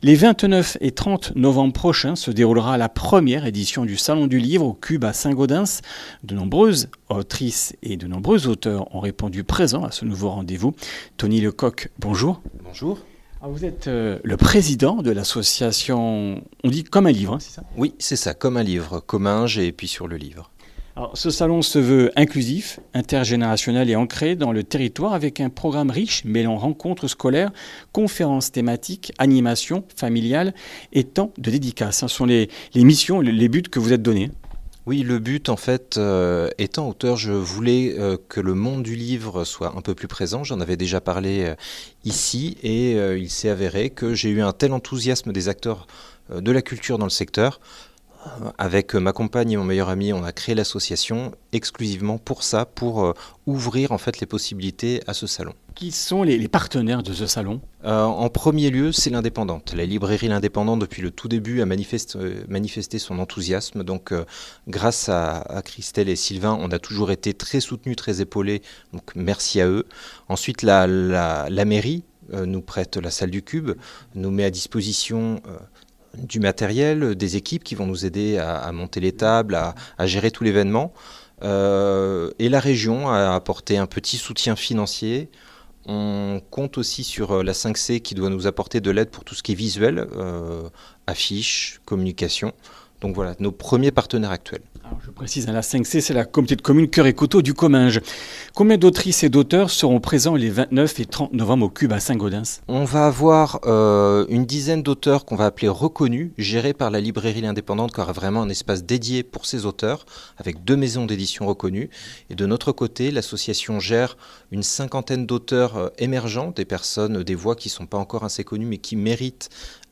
Comminges Interviews du 19 août